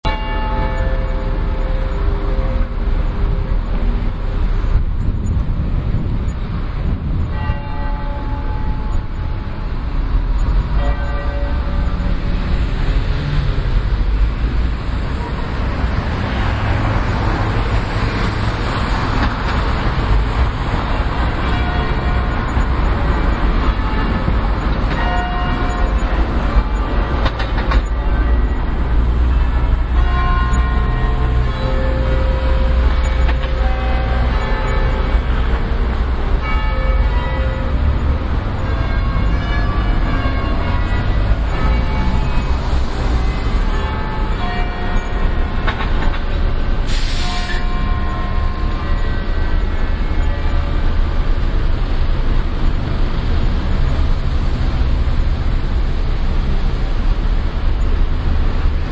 描述：重拾和调制我的朋友唱歌的声音，在后期制作中被摧毁。
标签： 声景 环境 人工 空间 教堂钟 多样品 合成
声道立体声